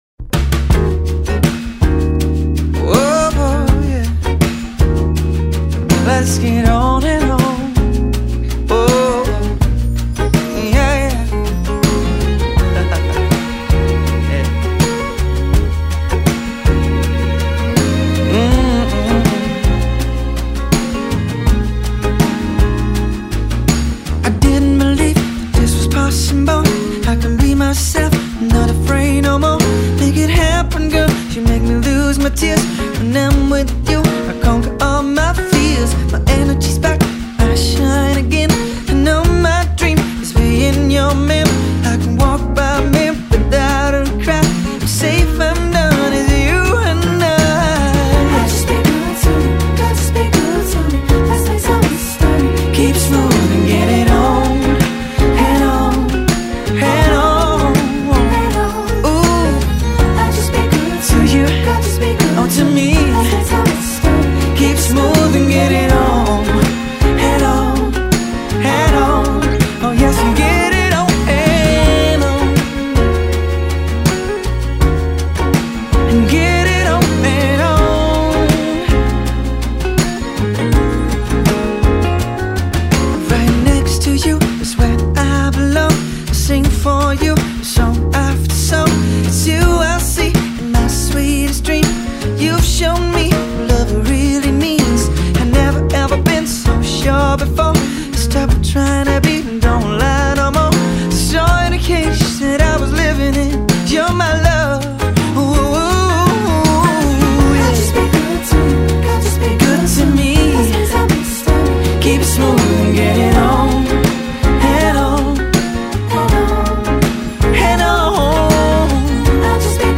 Soul / Funk.
Groovy sounds combined with soulful lyrics